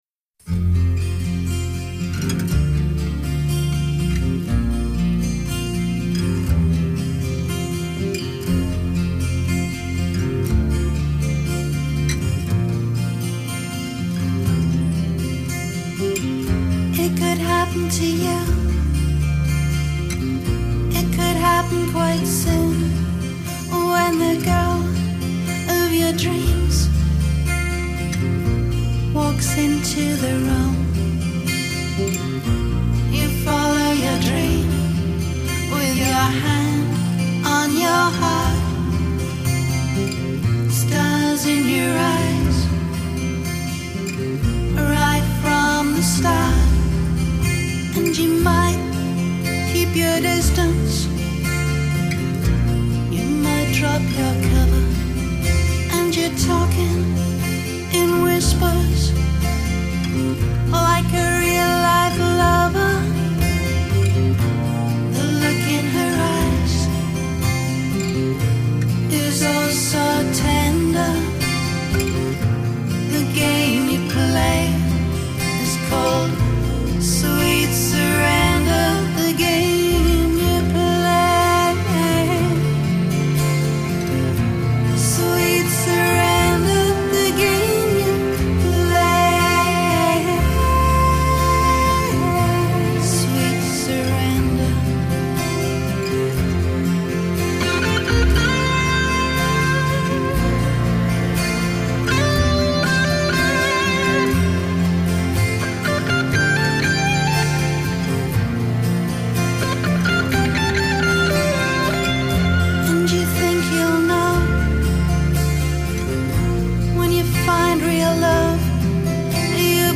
音乐风格: New Age / Celtic